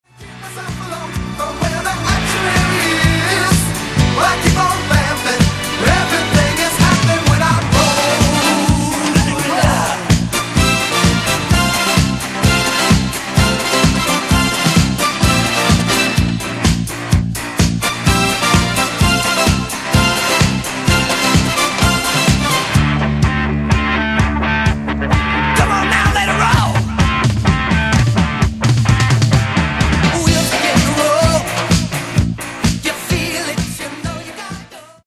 Genere:   Disco